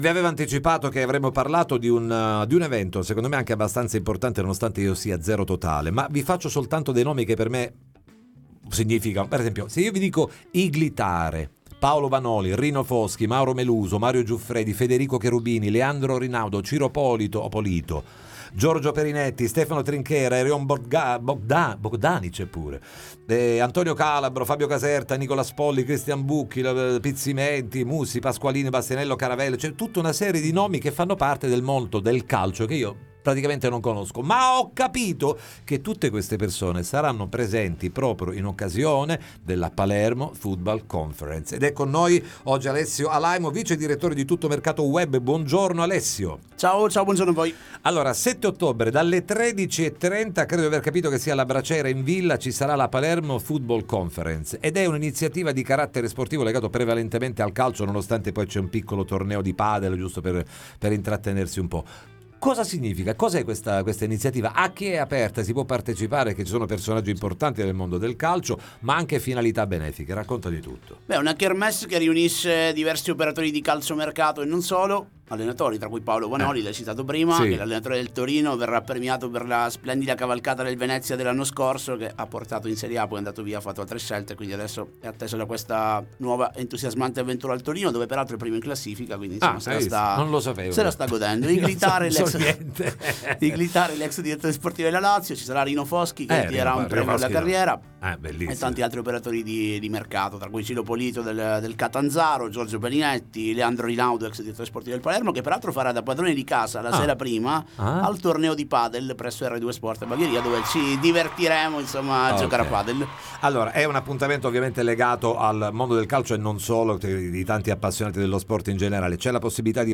Palermo Football Conference, ne parliamo con gli organizzatori ai ns. microfoni